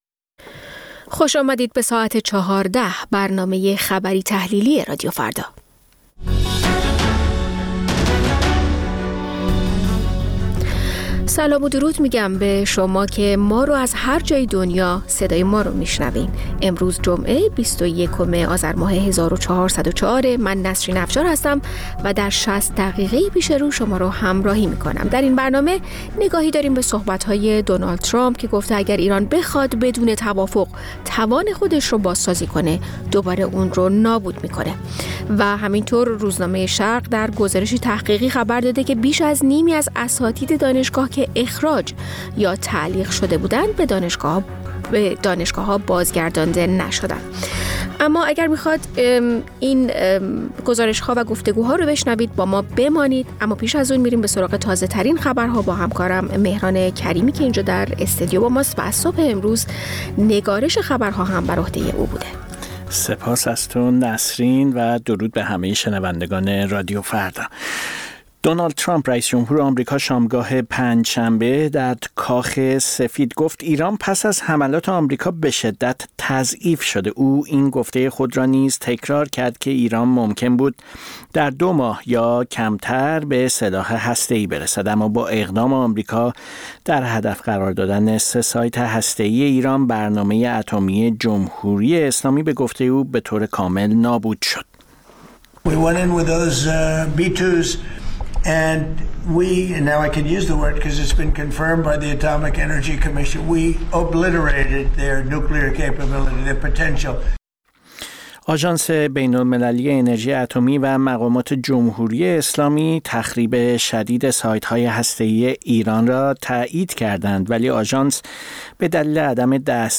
مجموعه‌ای از اخبار، گزارش‌ها و گفت‌وگوها در ساعت ۱۴